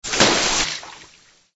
SA_watercooler_spray_only.ogg